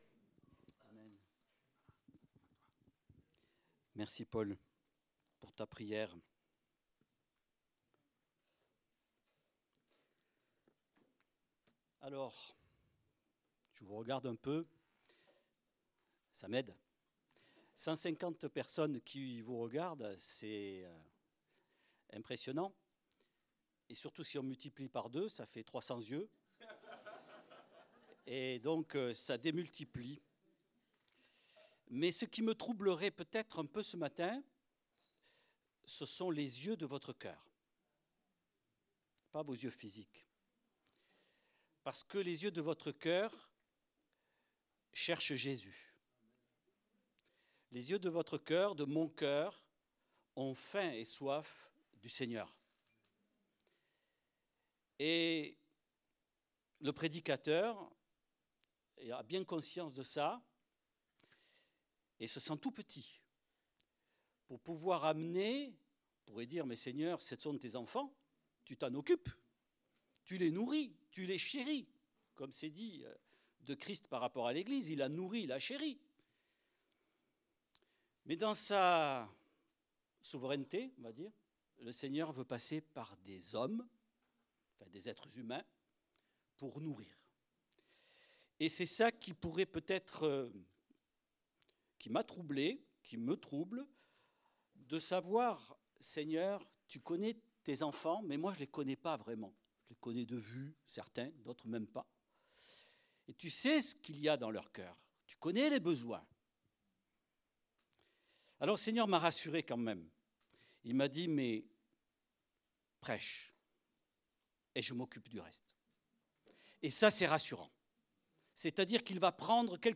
Culte du dimanche 13 avril 2025